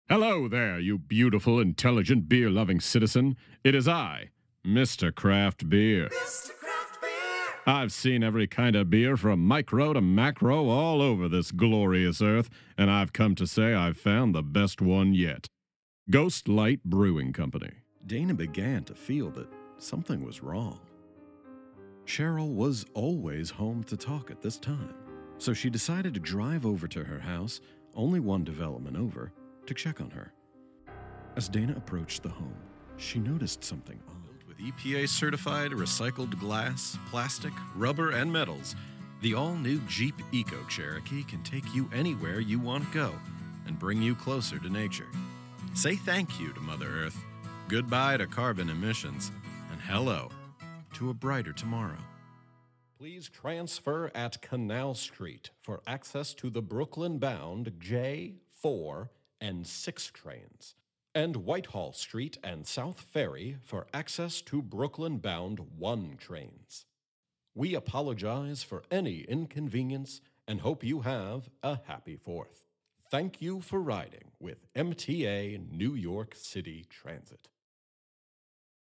Adult (30-50)
Radio & TV Commercial Voice